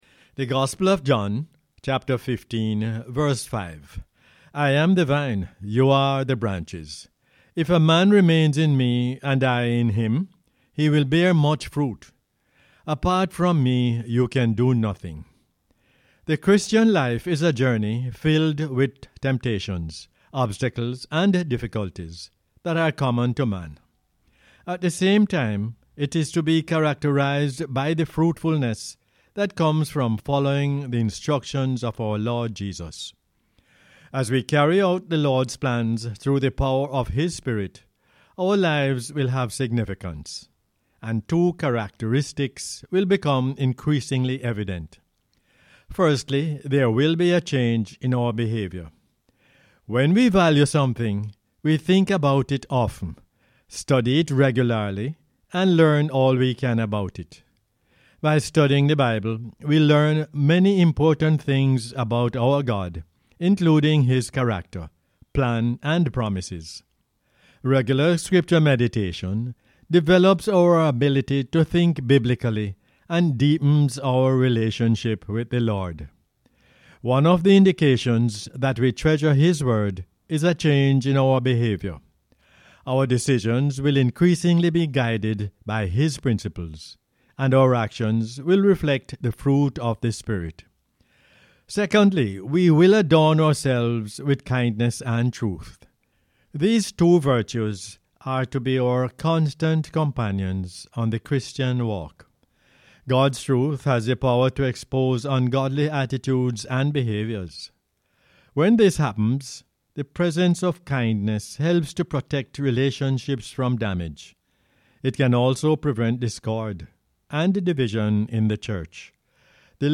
John 15:5 is the "Word For Jamaica" as aired on the radio on 10 July 2020.